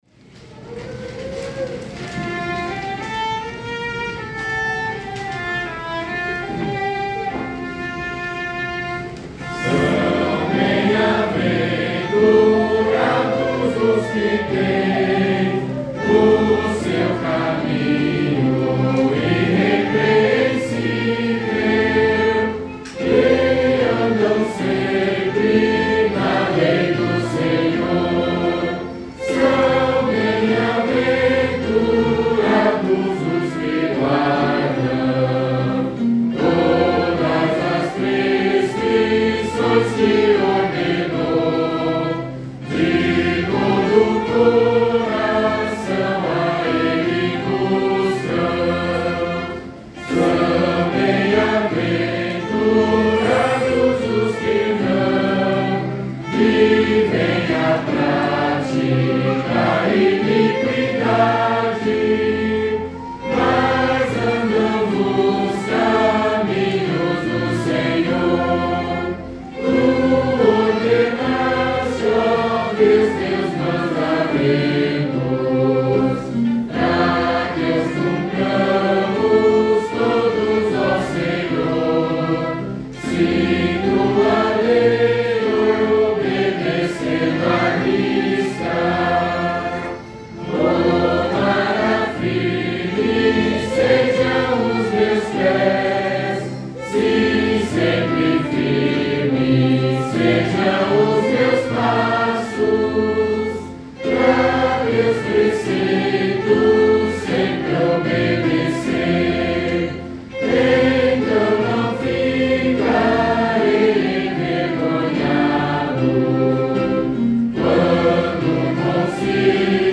Modo: hipojônio
salmo_119_1_8A_cantado.mp3